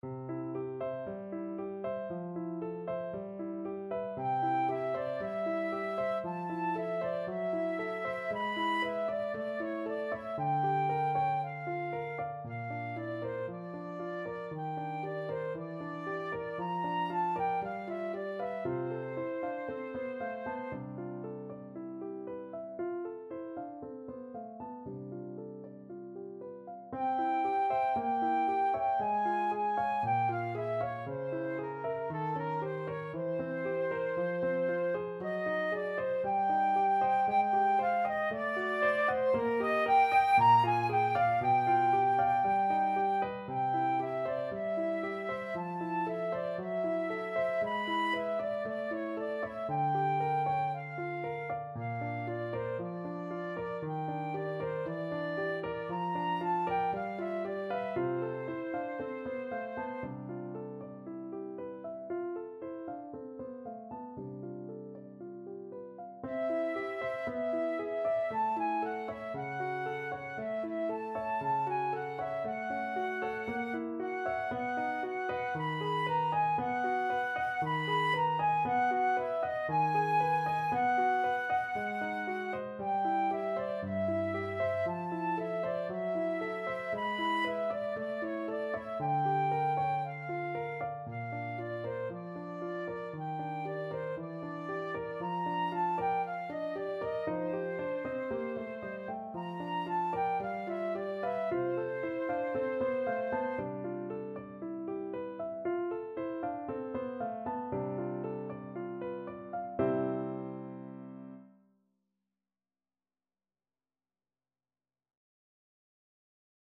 Flute version
4/4 (View more 4/4 Music)
Allegro moderato =116 (View more music marked Allegro)
Classical (View more Classical Flute Music)